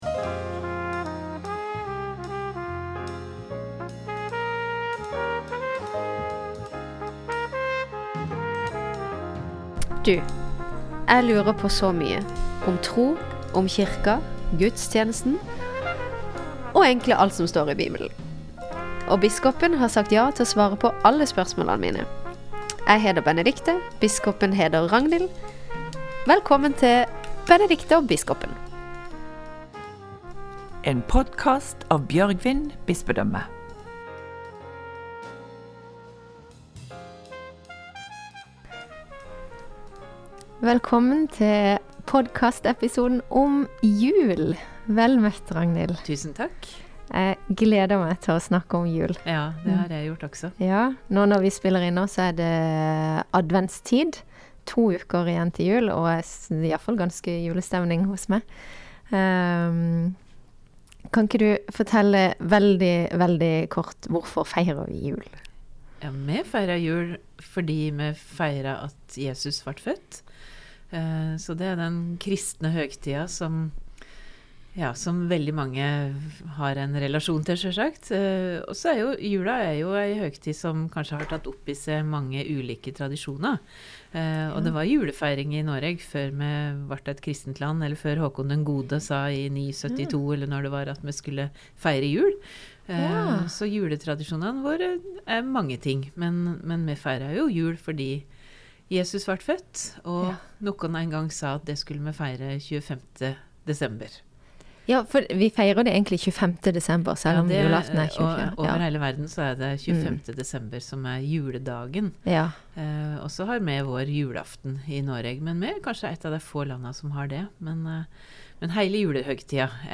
Kvar les vi om Jesu fødsel i Bibelen, kva er forskjellen på julefortelingane i Bibelen, kven var Herodes, og korleis feirar vi jul i kyrkja? Dette og mykje anna kan du høyre om i denne episoden, som òg har ei lita musikalsk overrasking på slutten.